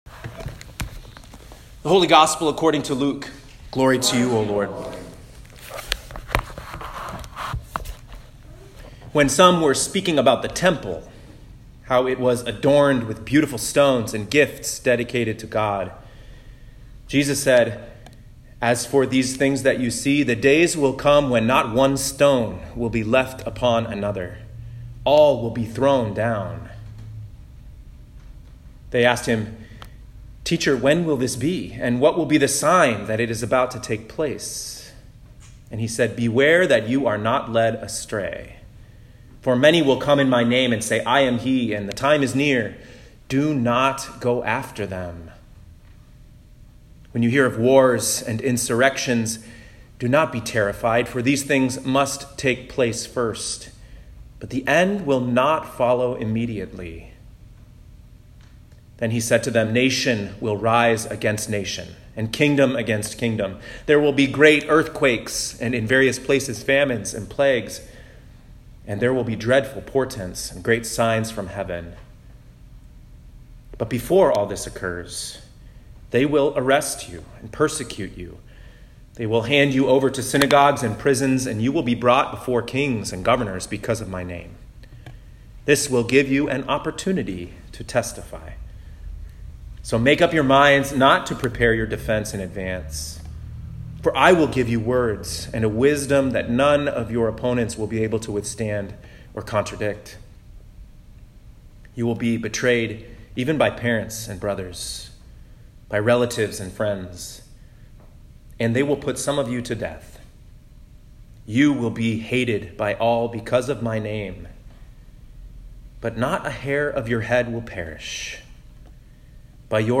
Twenty-Third Sunday after Pentecost, Year C (11/17/2019)